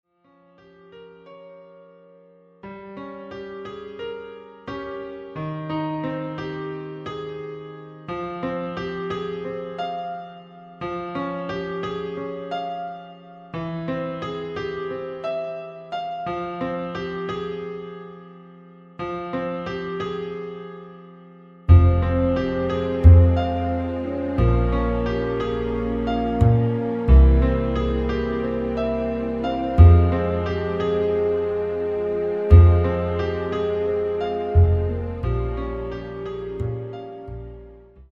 DEMO MP3 MIDI